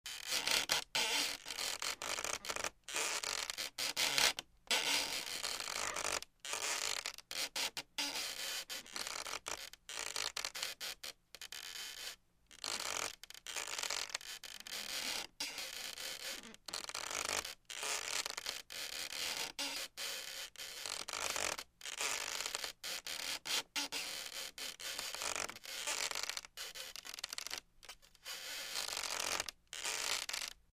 Звуки скрипов
Скрипт пластика